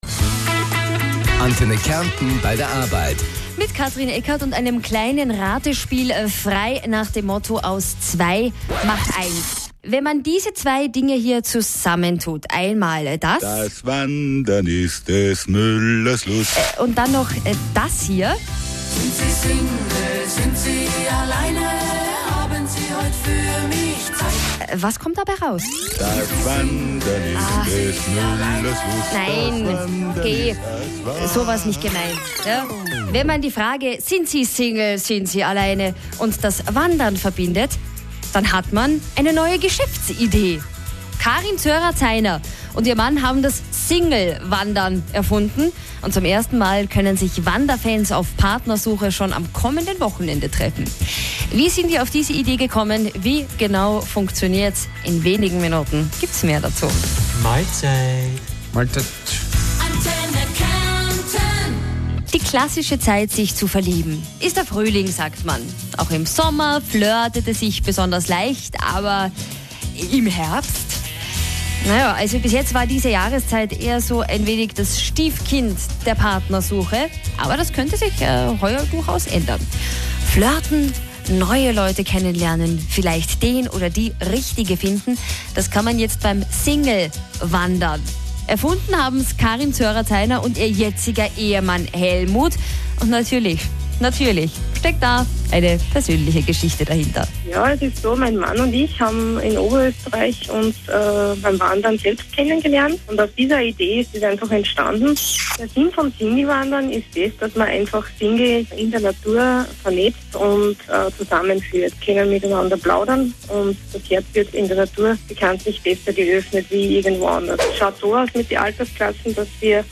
Antenne Kärnten Interview
Heute Mittag wurde dann der daraus entstandene und wirklich nette Beitrag in Kärnten gesendet :-)